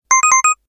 powerUp11.ogg